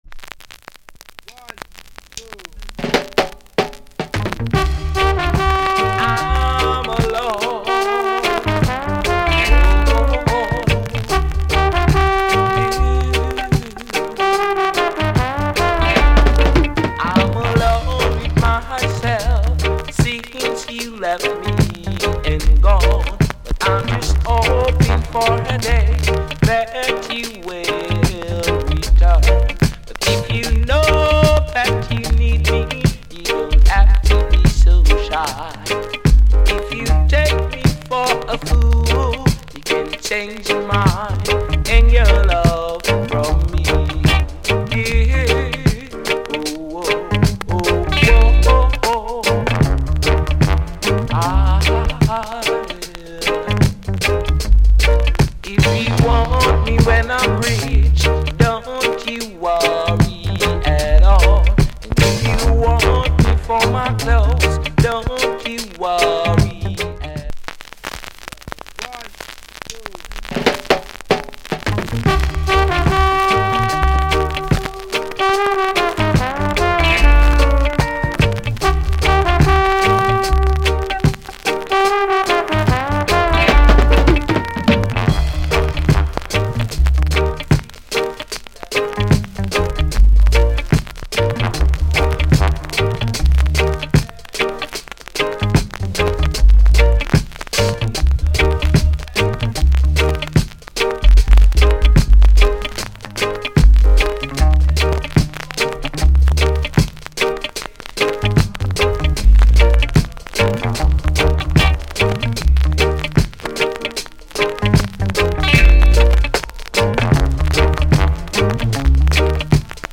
Production UK Genre Reggae70sMid / Male Vocal Condition VG+